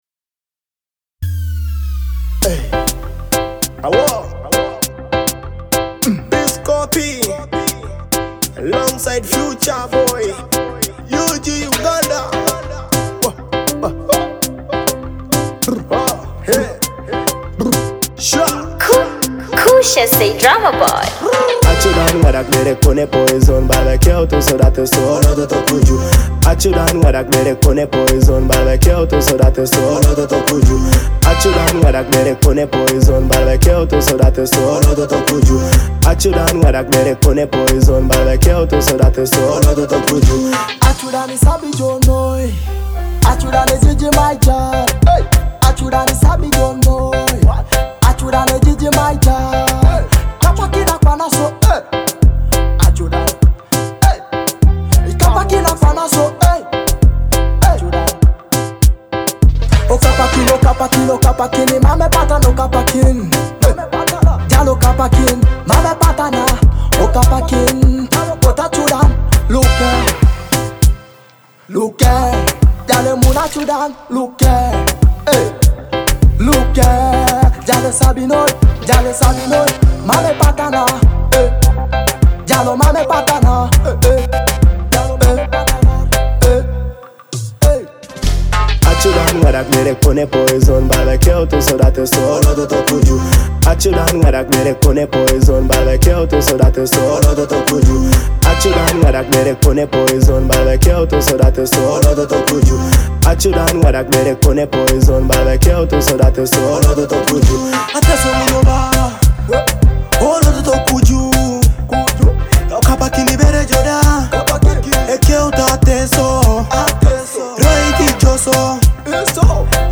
Blending Afro-dancehall rhythms with East African flavor